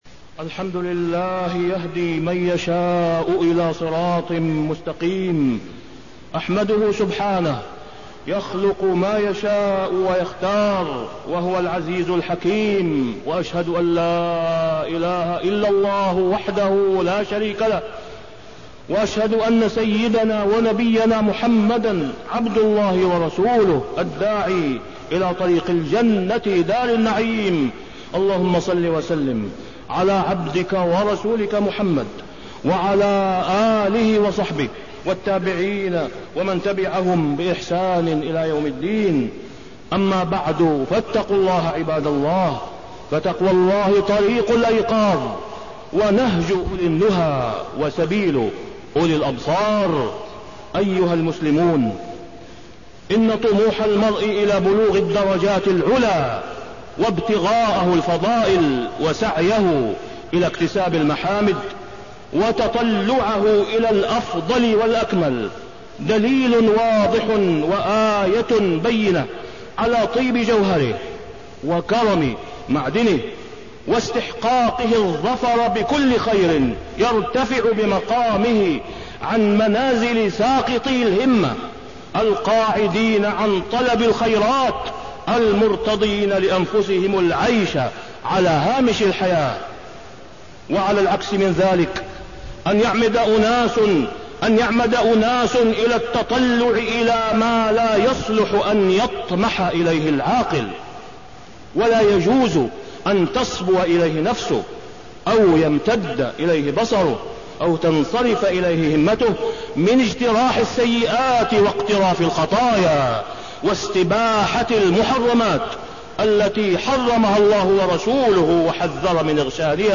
تاريخ النشر ١١ شعبان ١٤٣١ هـ المكان: المسجد الحرام الشيخ: فضيلة الشيخ د. أسامة بن عبدالله خياط فضيلة الشيخ د. أسامة بن عبدالله خياط التنافس المحمود